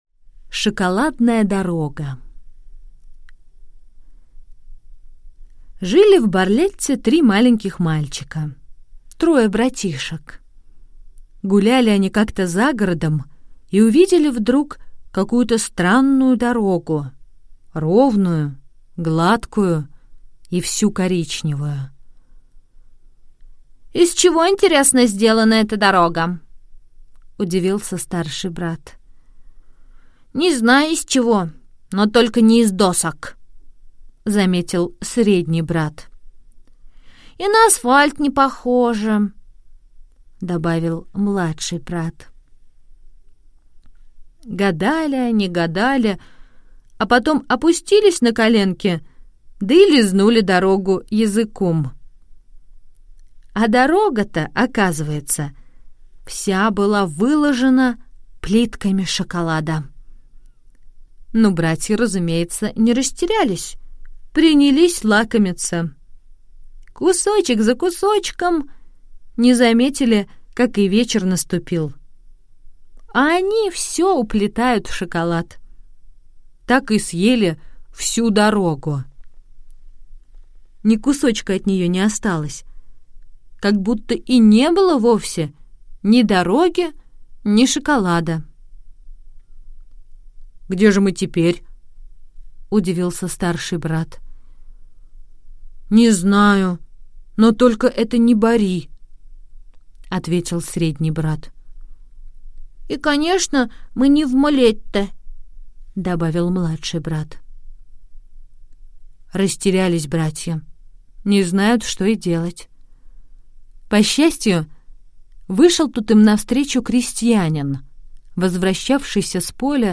На данной странице вы можете слушать онлайн бесплатно и скачать аудиокнигу "Шоколадная дорога" писателя Джанни Родари. Включайте аудиосказку и прослушивайте её на сайте в хорошем качестве.